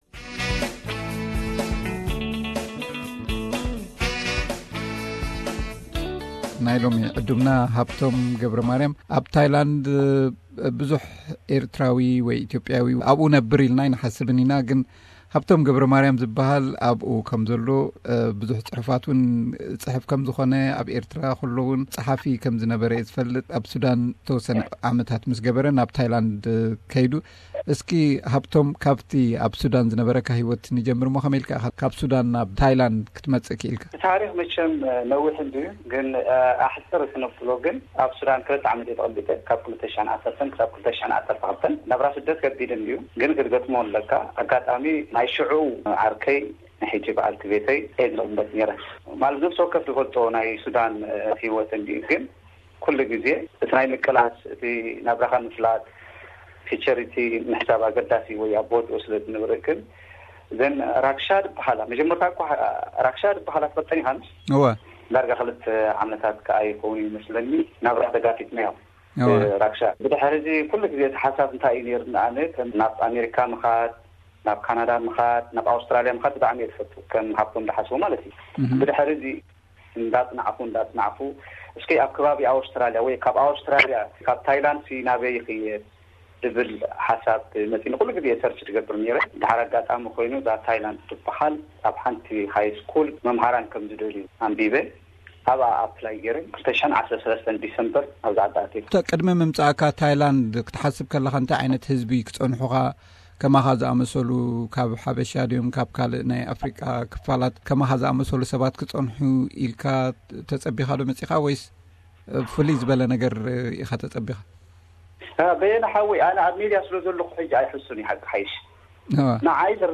ናትና ሰብ ኣብ ታይላንድ፡ ዕላል